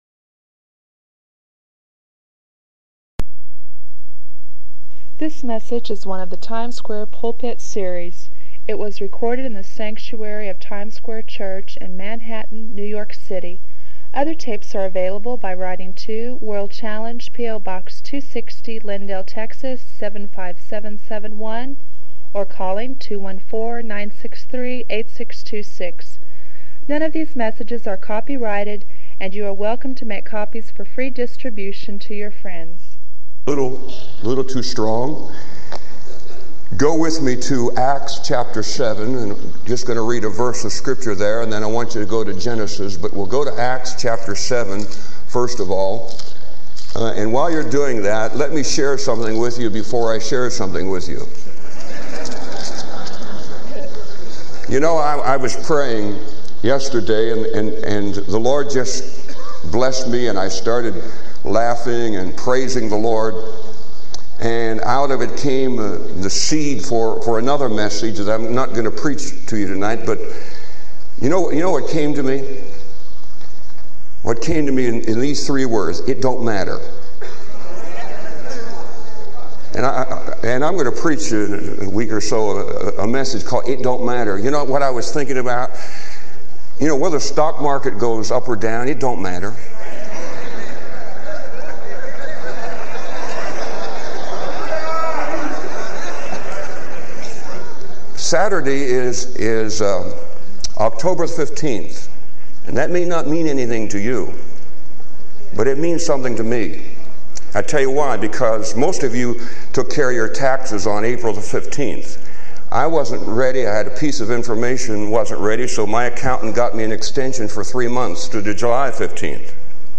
This sermon offers hope and clarity for those who feel they have not yet truly known Jesus.